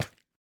Minecraft Version Minecraft Version 1.21.5 Latest Release | Latest Snapshot 1.21.5 / assets / minecraft / sounds / block / candle / step5.ogg Compare With Compare With Latest Release | Latest Snapshot